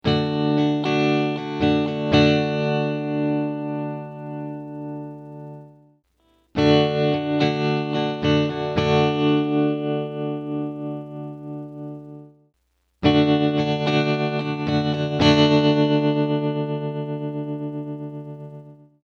A classic tremolo.
Clip 1: 0% Depth
all clips: Rate at 0%, then 50%, then 100%
guitar - effect - cabinet simulator - sound card